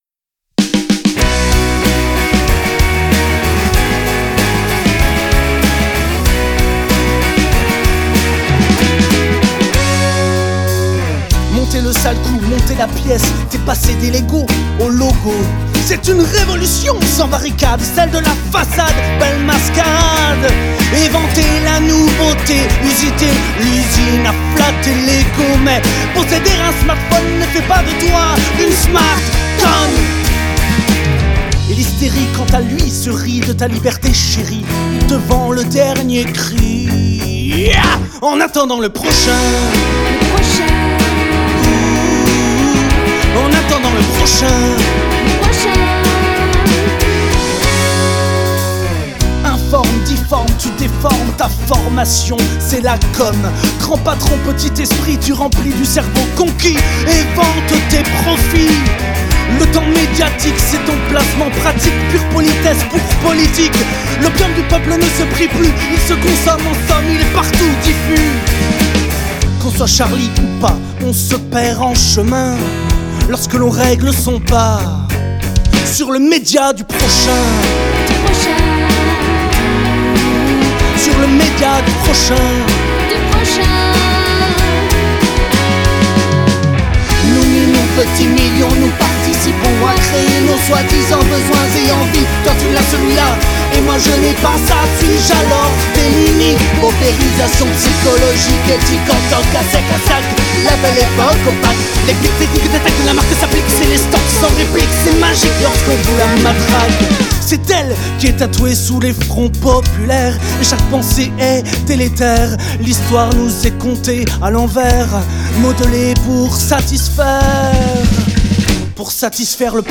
trio poético-rock